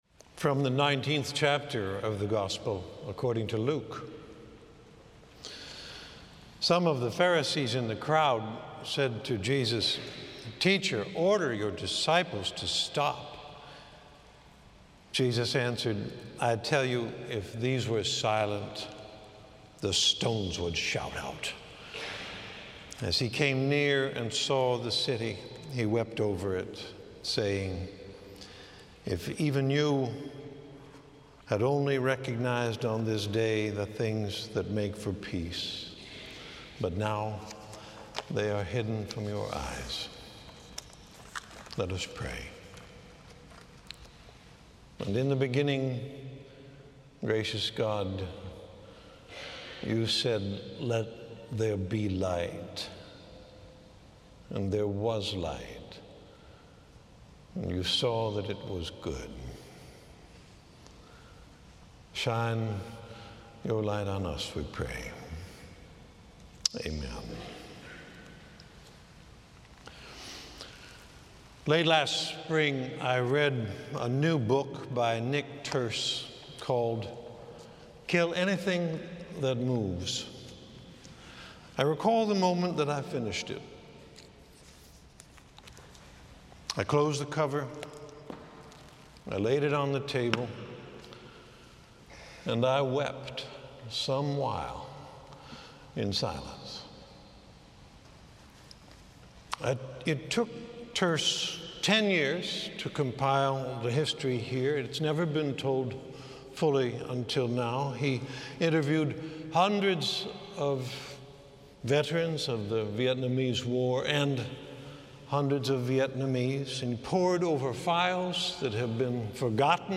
(from the sermon series Learner’s Mind, interpreting the Elisha cycle, which began with Decision)